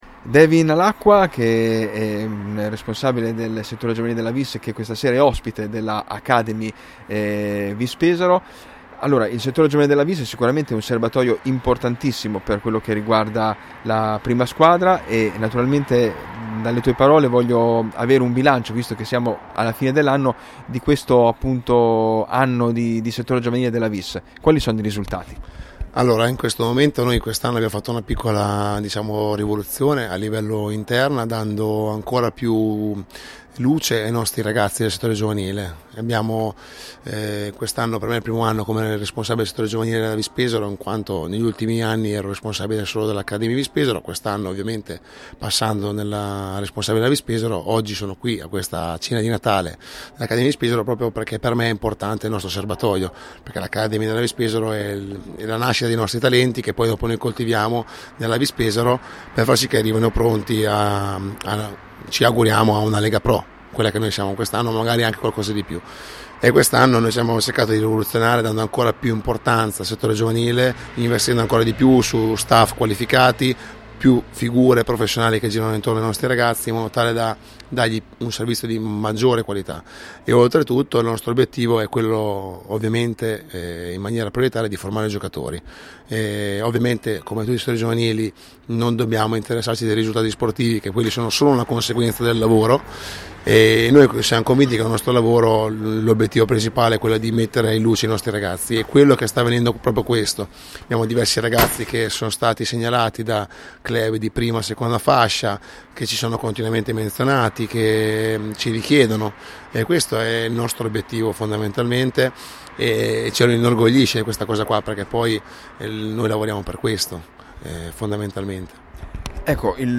Si è svolta ieri sera, alla Trattoria Adriatica di Pesaro, la cena di fine anno dedicata alla Vis Pesaro Academy.